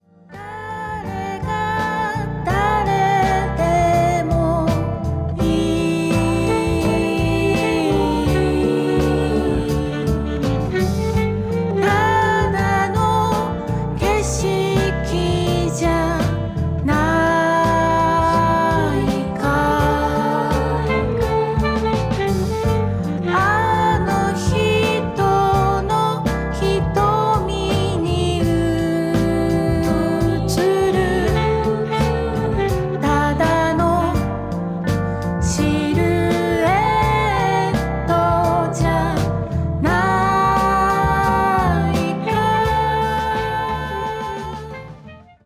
儚くも豊かなメロディーを持つ楽曲
彼らのアングラ的な部分よりも、素朴な中に高い音楽性（と中毒性）が見える、幽玄でメロディアスな好選曲の内容となっています。